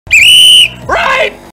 Sound Buttons: Sound Buttons View : Jontron Whistle
rape-whistle-jontron.mp3